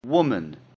Woman.mp3